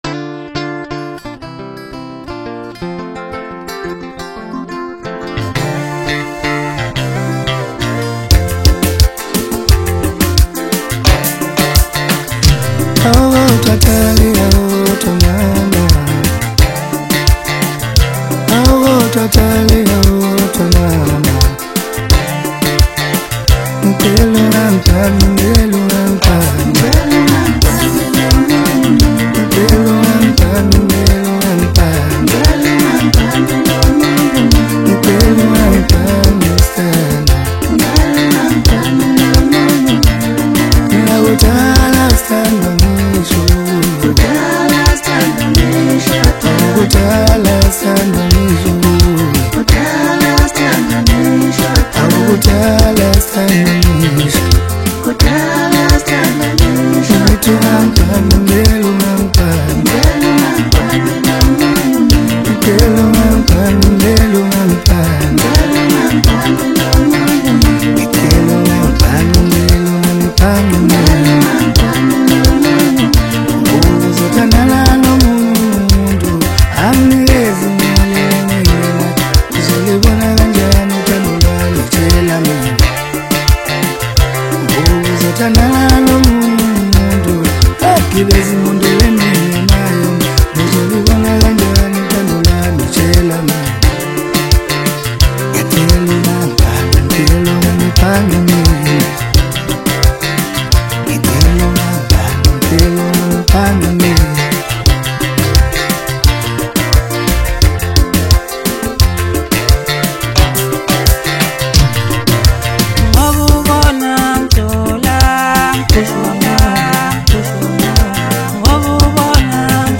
Home » Maskandi » Maskandi Music